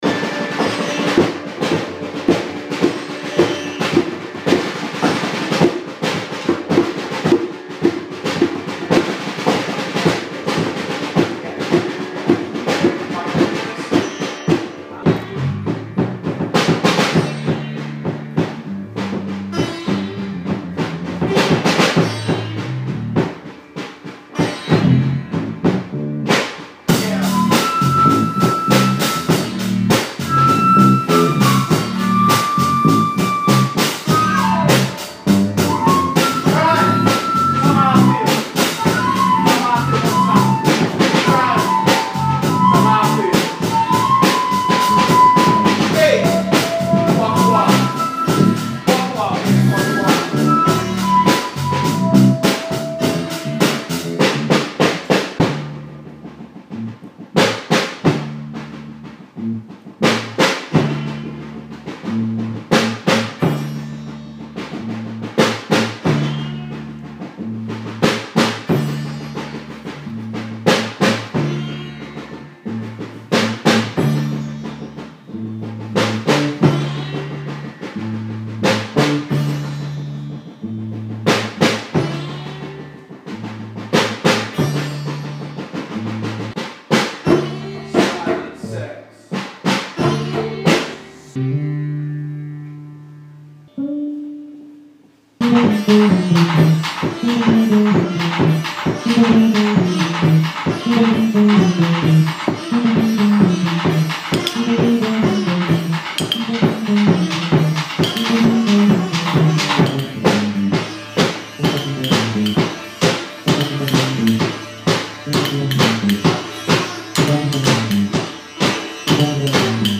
drums
bass
flute
voice/sitar